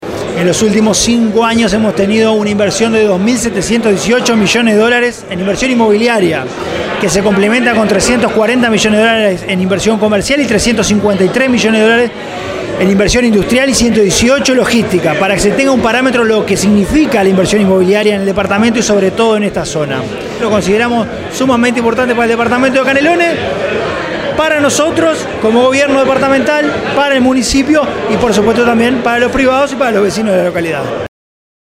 El martes 19 de julio, se realizó el lanzamiento del Foro de Arquitectura y Urbanismo, organizado por la Editorial AyD y promovido por la Intendencia de Canelones a través de la Agencia de Promoción a la Inversión (API).
francisco_legnani_-_secretario_general.mp3